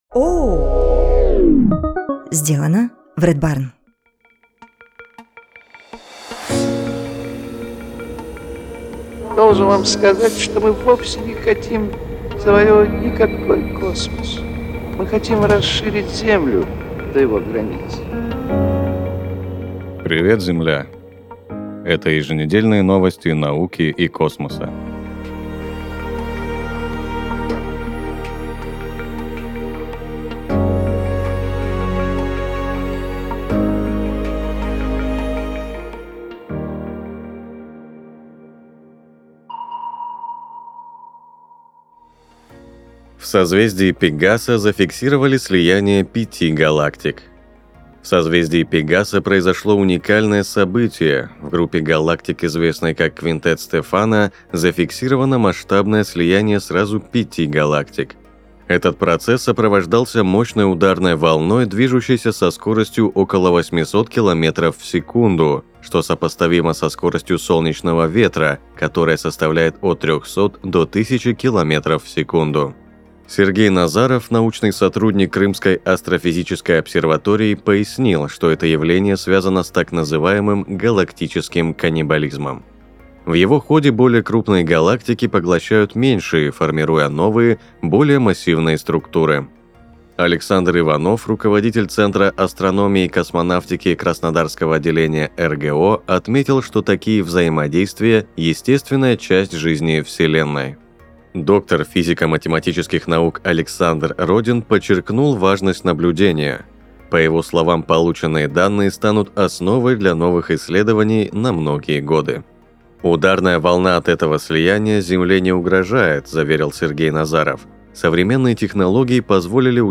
Ведет выпуск